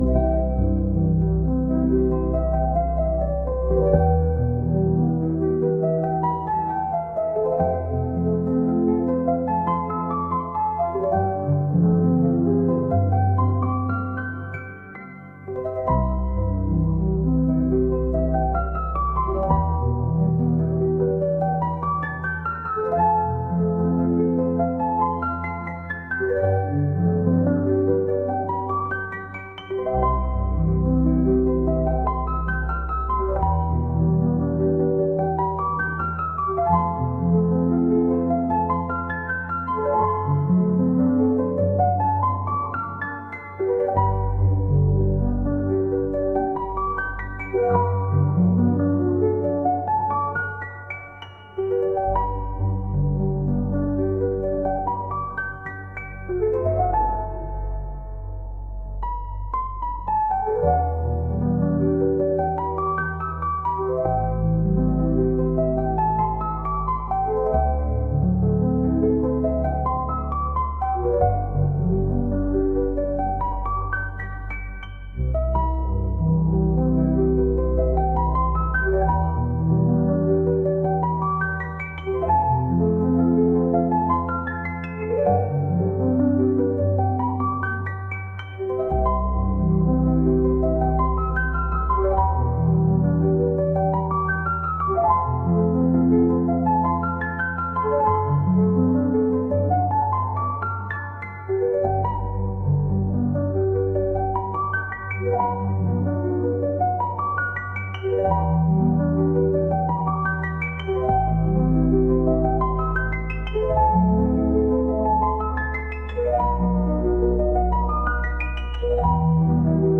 「癒し、リラックス」